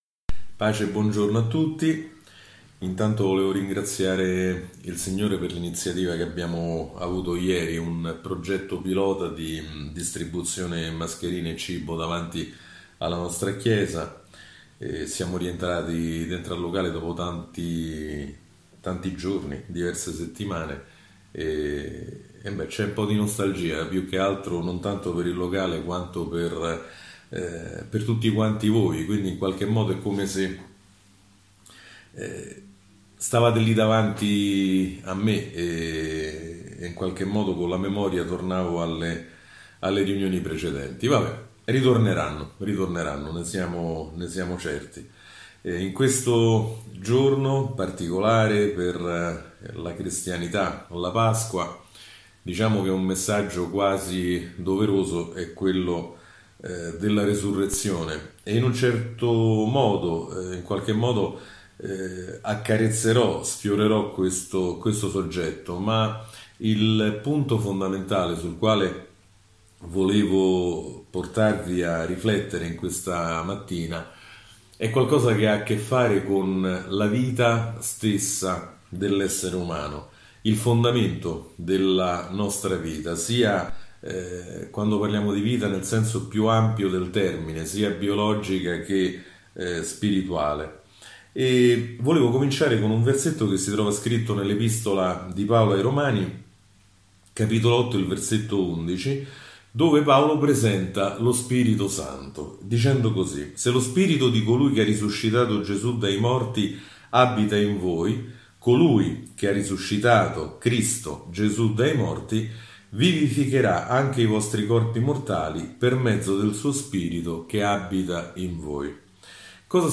Sermoni – Pagina 5 – Chiesa del Grano